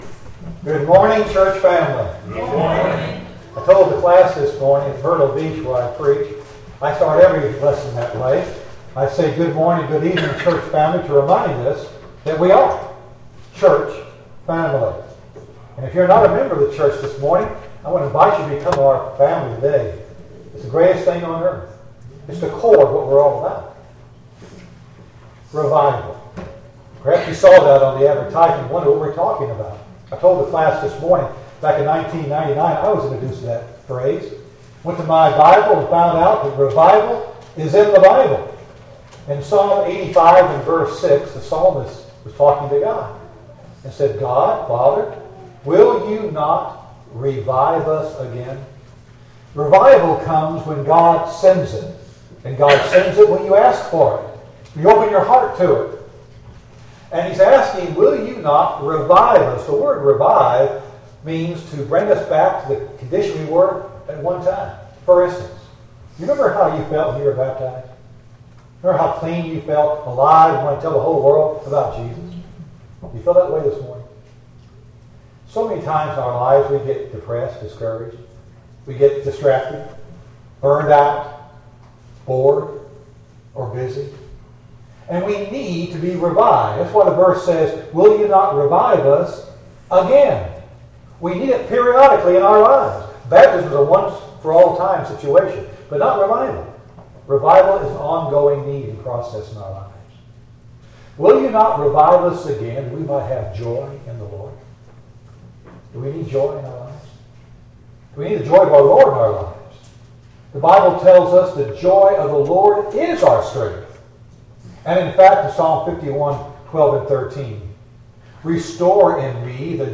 II Corinthians 5:10 Service Type: Gospel Meeting Topics